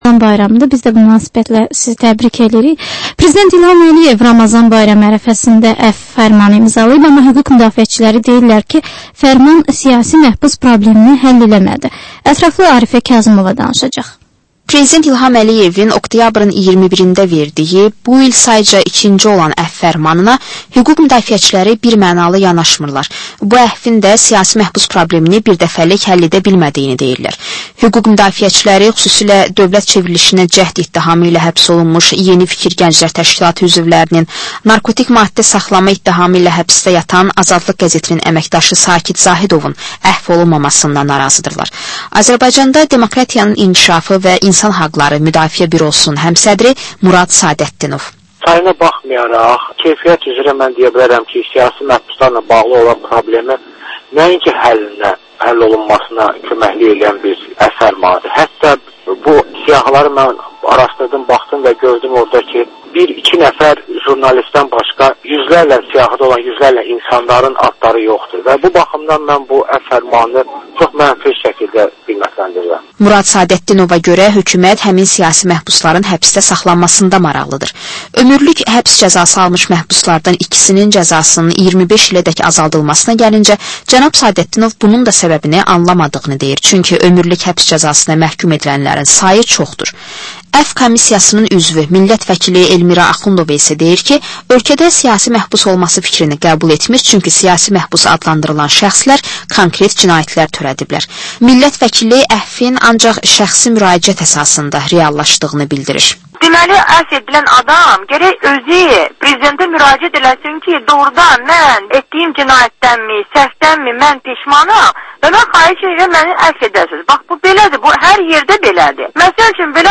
Həftənin mədəniyyər xəbərləri, reportajlar, müsahibələr